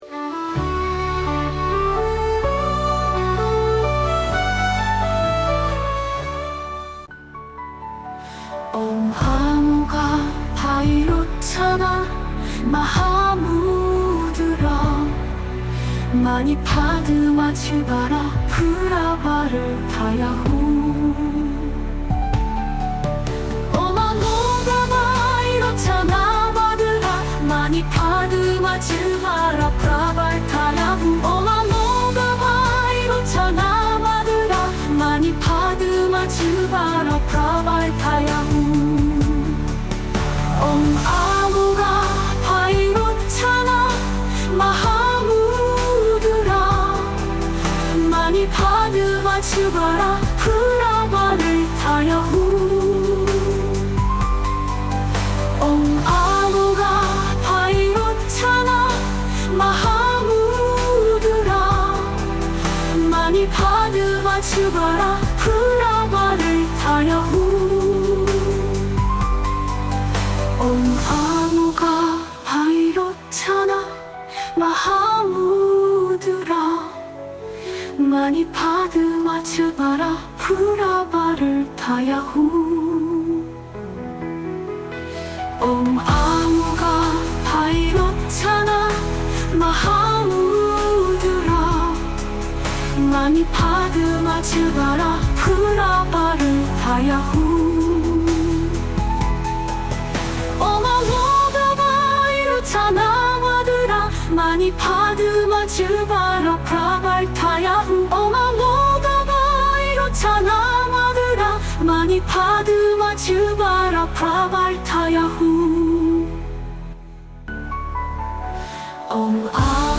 광명진언을 듣기 좋고 따라하기 쉽게 발라드 노래로 만들어 보았습니다.